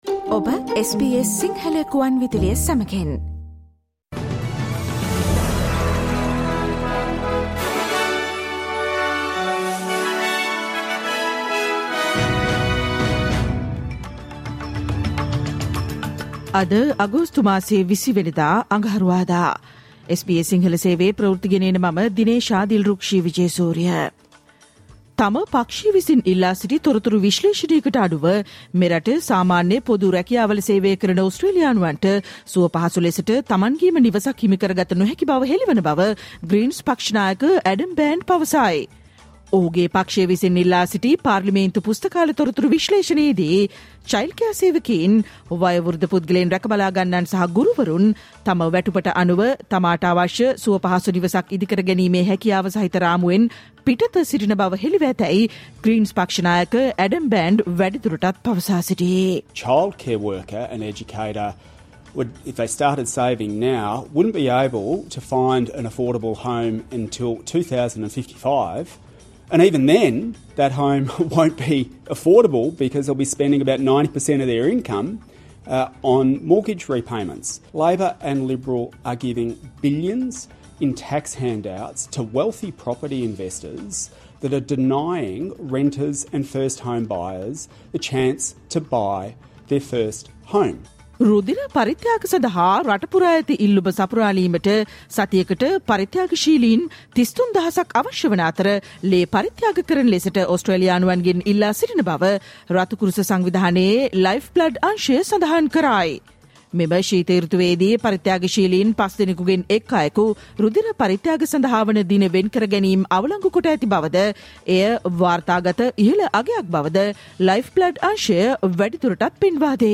Australian news in Sinhala, foreign news, and sports news briefly summarised - listen to SBS Sinhala radio News Flash on Tuesday, 20 August 2024